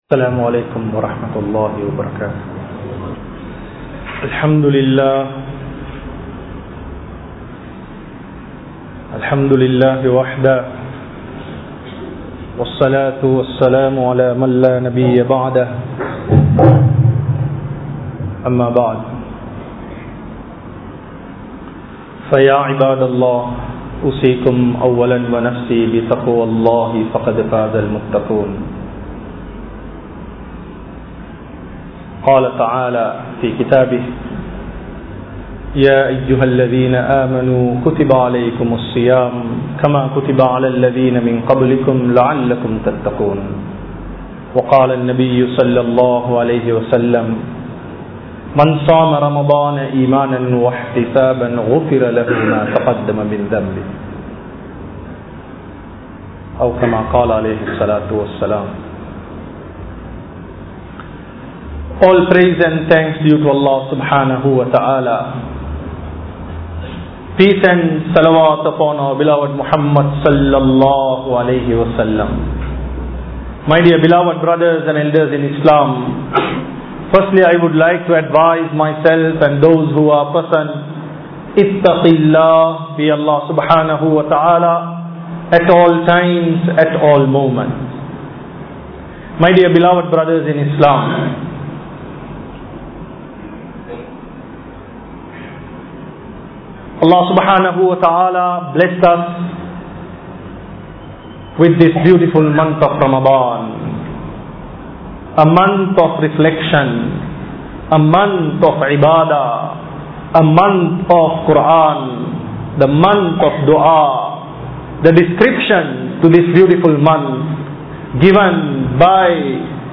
The Change In Ramalan | Audio Bayans | All Ceylon Muslim Youth Community | Addalaichenai